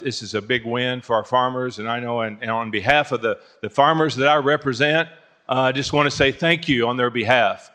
State Senator Dale Fowler applauded Joyce for his efforts.